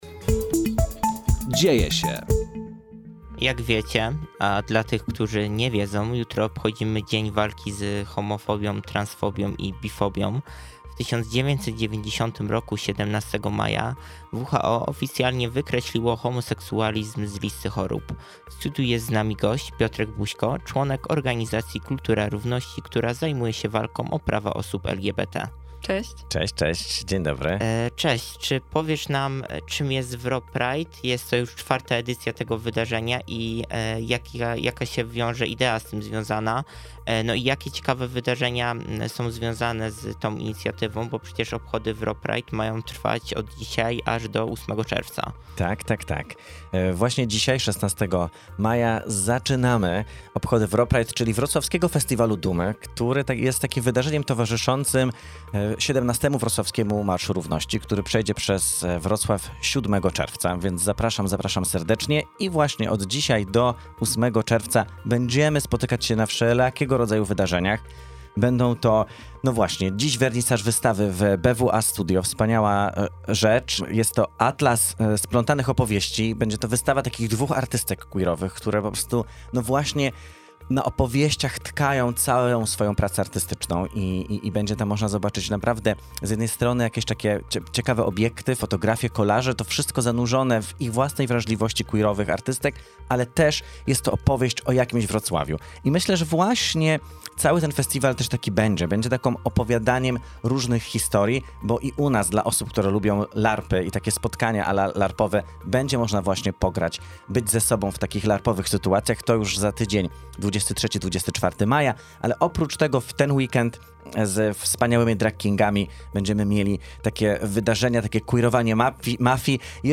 Powodem rozmowy był dzień walki z homofobią, transfobią i bifobią.